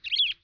bird3.wav